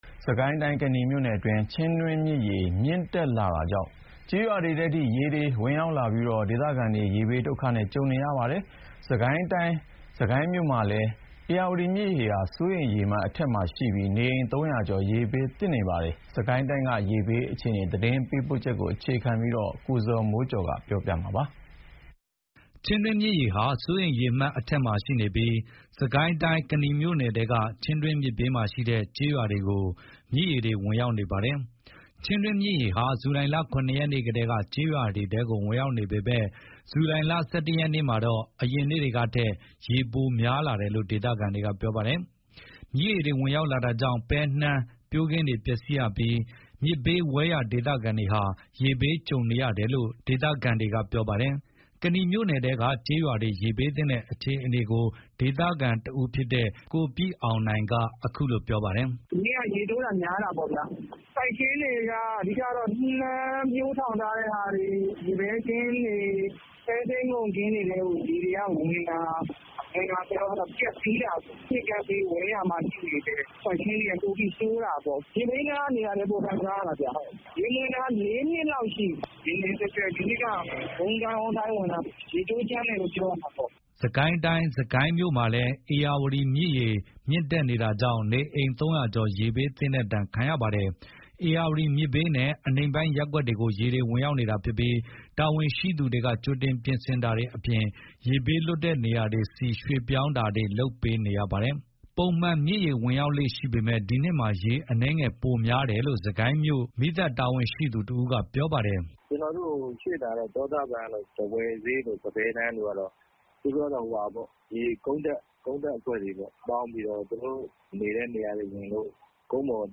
အခုလို မြစ်ကမ်းဘေးက ကျေးရွာတွေမှာ နှစ်စဥ် ပုံမှန်မြစ်ရေဝင်ရောက်လေ့ရှိပေမယ့် ဒီနှစ်မှာ ရေအနည်းငယ်ပိုတက်တယ်လို့ စစ်ကိုင်းမြို့ မြစ်ရေအခြေအနေကို စစ်ကိုင်းမြို့ မီးသတ်တာဝန်ရှိသူတဦးကအခုလို ပြောပါတယ်။